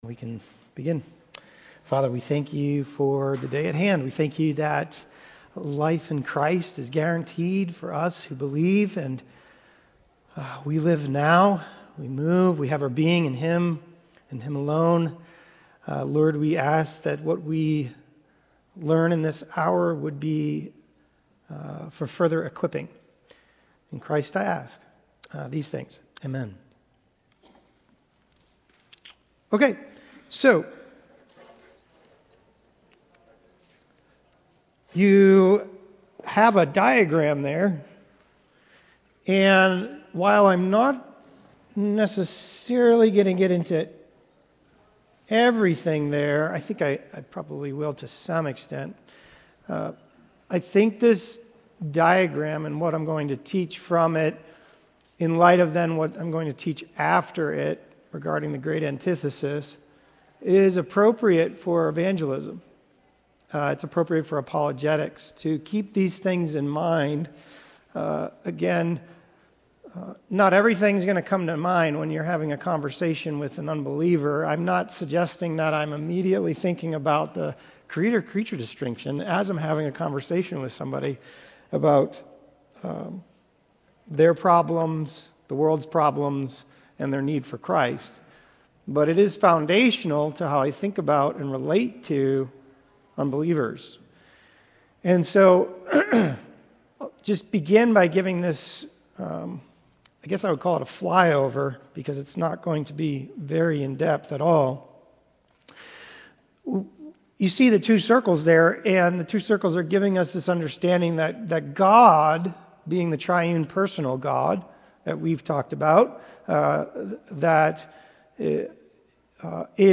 Lesson-6-Man-and-the-Great-Antithesis.mp3